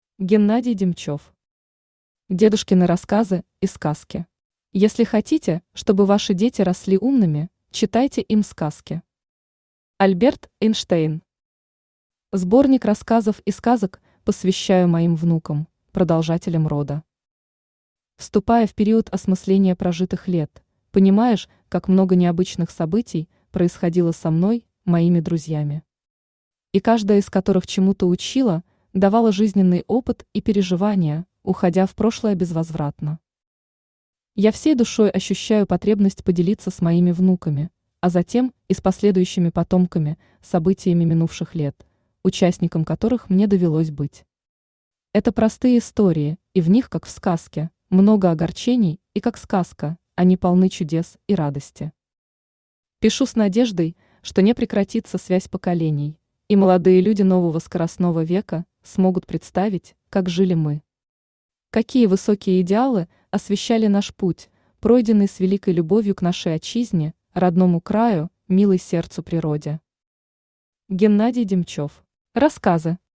Аудиокнига Дедушкины рассказы и сказки | Библиотека аудиокниг
Aудиокнига Дедушкины рассказы и сказки Автор Геннадий Васильевич Демчев Читает аудиокнигу Авточтец ЛитРес.